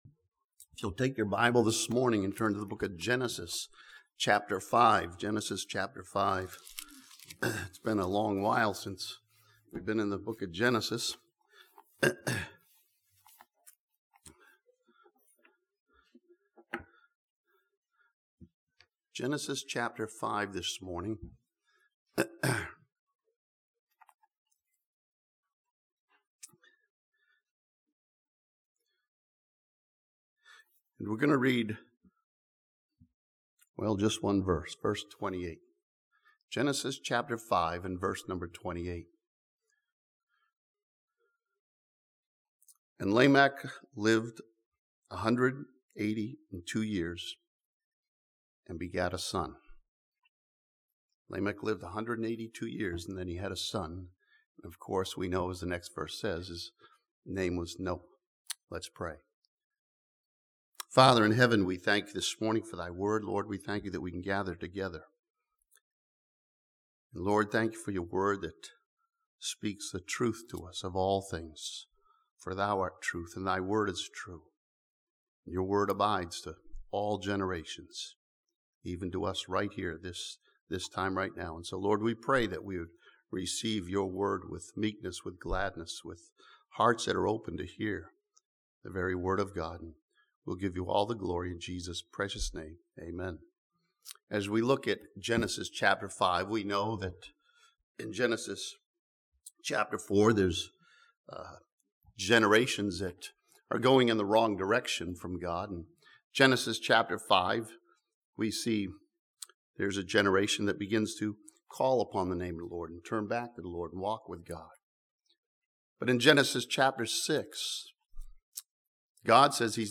This sermon from Genesis chapter 5 studies Noah's life and find some lessons for us as the world gets worse.